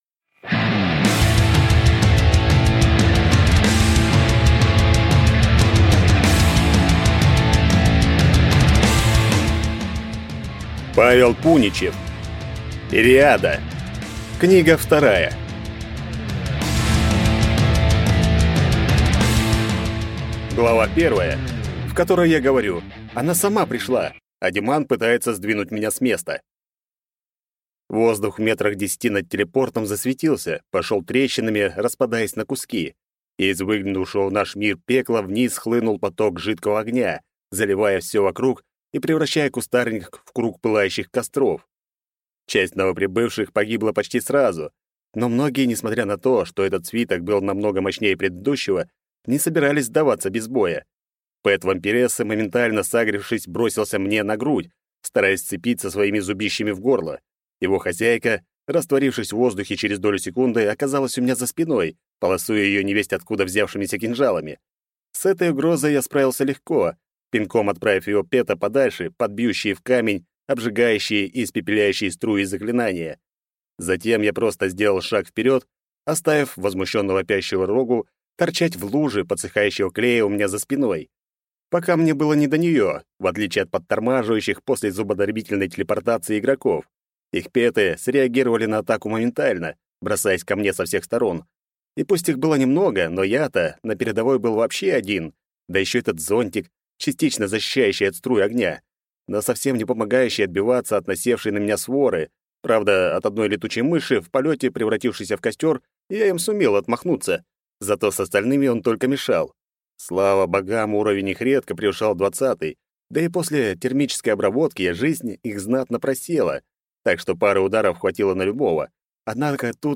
Аудиокнига Ириада 2 | Библиотека аудиокниг